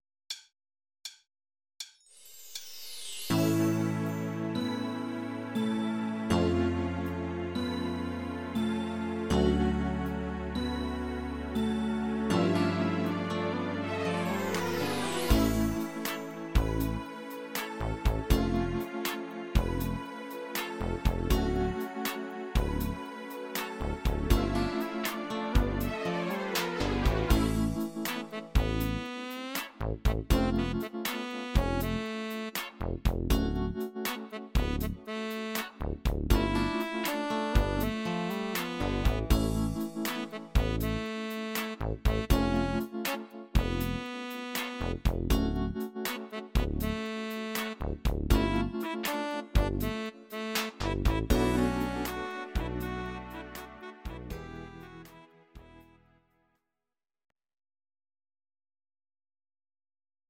Demo/Koop midifile
- GM = General Midi level 1